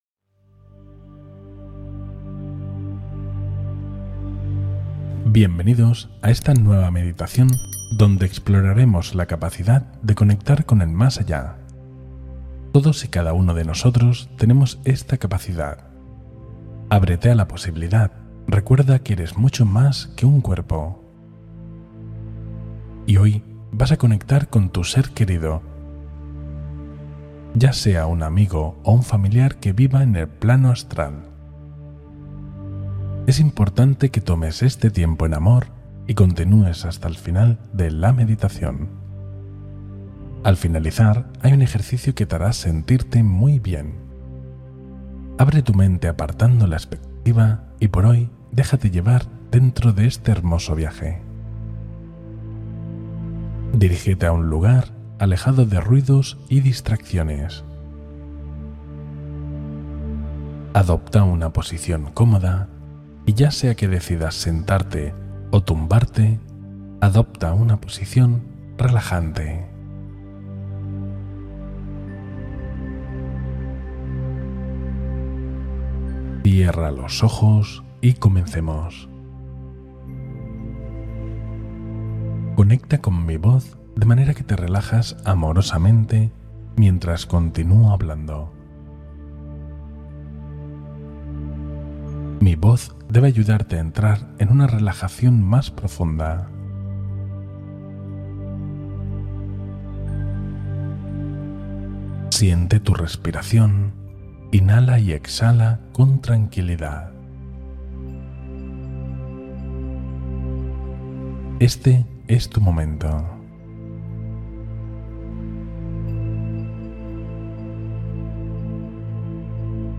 Conexión Emocional con un Ser Querido: Meditación de Presencia Sutil